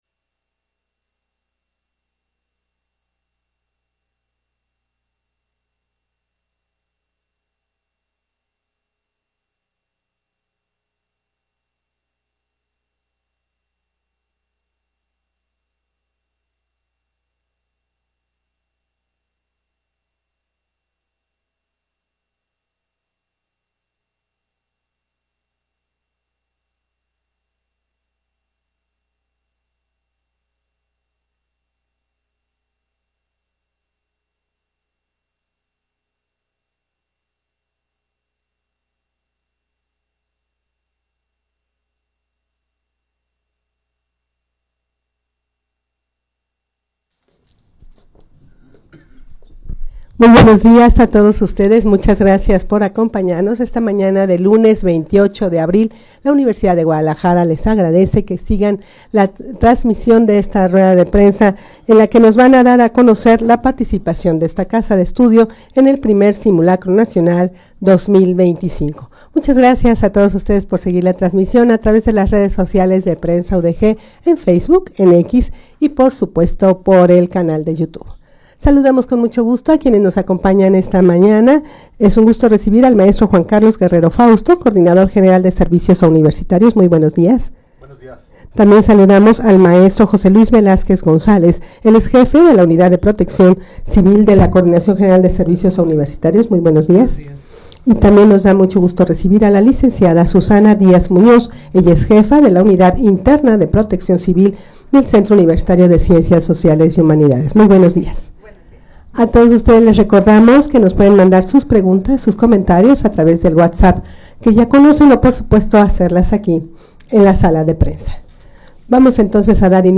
Audio de la Rueda de Prensa
rueda-de-prensa-participacion-de-esta-casa-de-estudio-en-el-1er-simulacro-nacional-2025.mp3